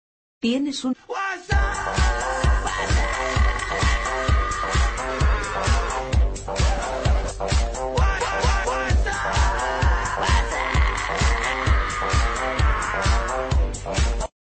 Categoría Whatsapp